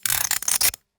unequip.wav